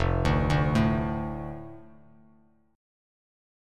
F6 Chord
Listen to F6 strummed